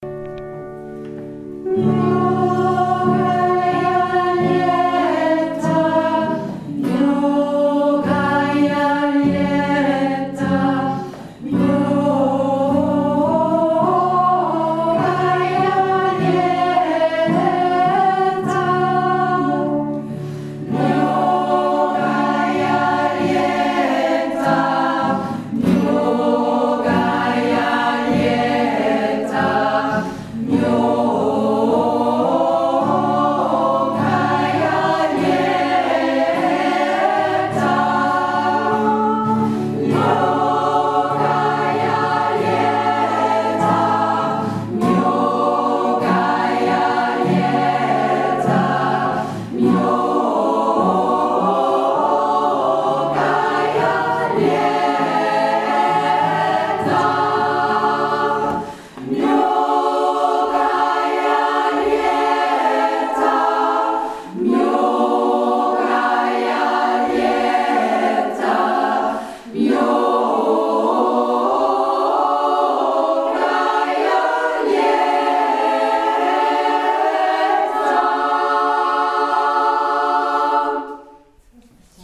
Unser russisches Segnunglied (1.2 MB)